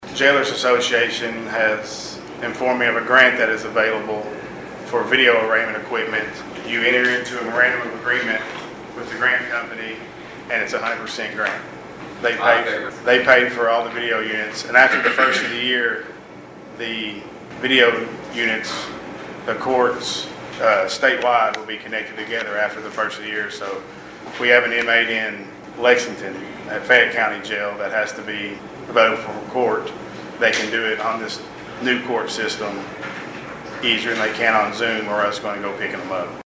click to download audioAt the October 24 fiscal court meeting, Caldwell County Jailer Willie Harper explained to magistrates that the grant would be used to acquire four units of video equipment, which would help reduce costs at the jail in the future.